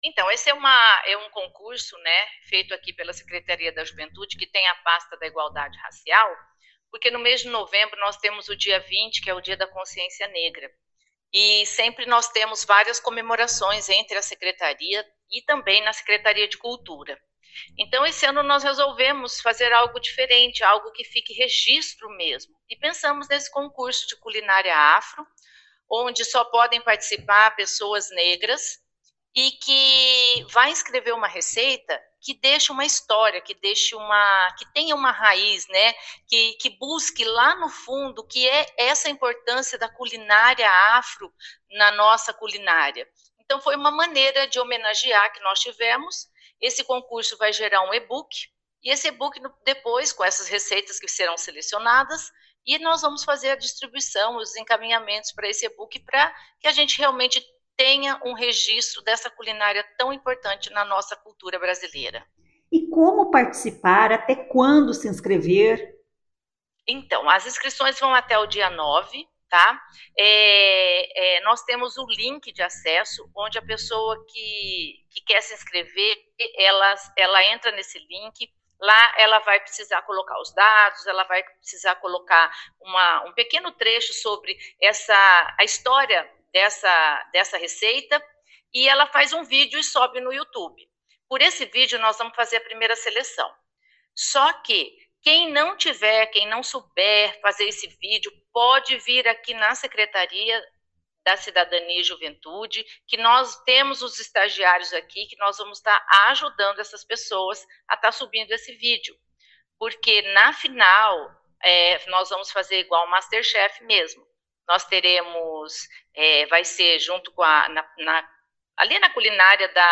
Ela explica quem pode participar e o objetivo do concurso: